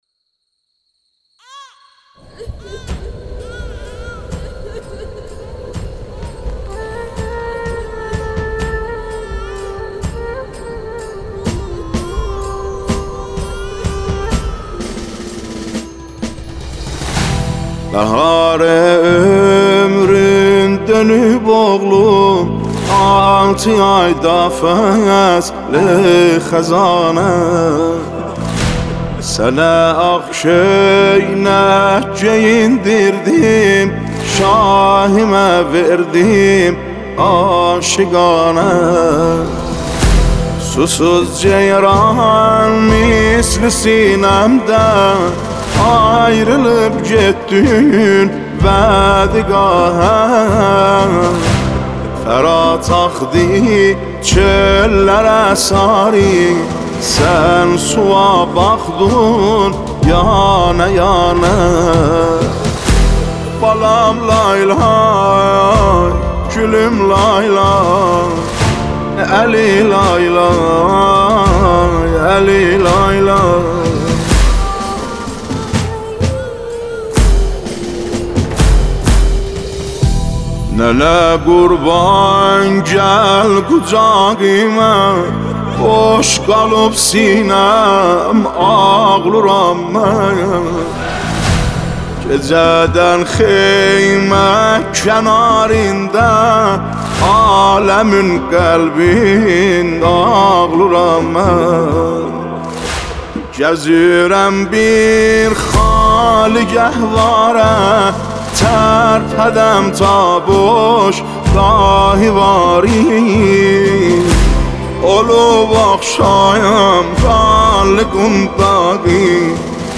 نوحه
سوزناک ترین مداحی
مداحی ترکی